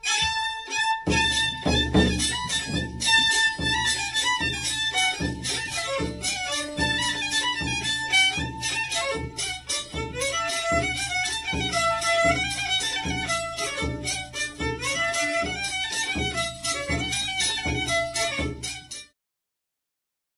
Tę wypowiedź przyjęliśmy jako motto dla płyty prezentującej najstarsze archiwalne nagrania ludowej muzyki skrzypcowej ze zbiorów Instytutu Sztuki.